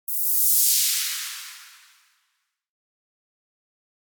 Download Noise sound effect for free.
Noise